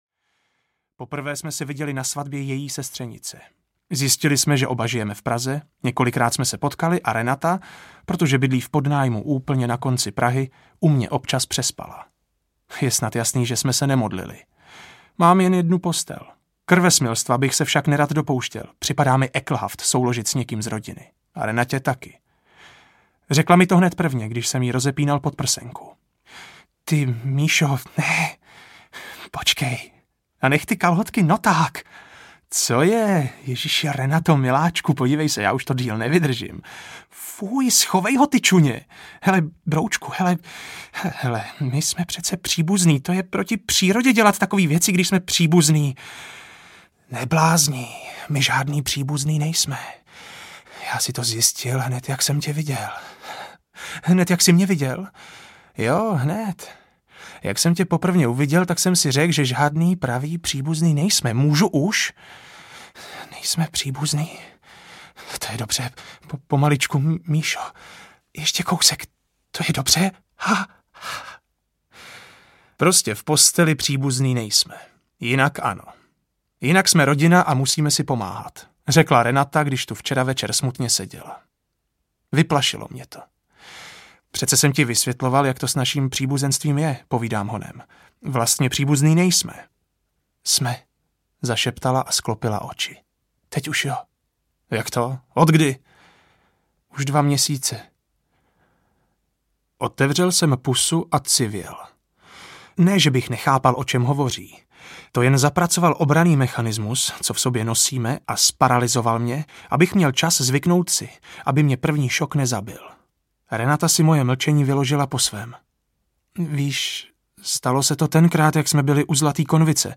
Vekslák audiokniha
Ukázka z knihy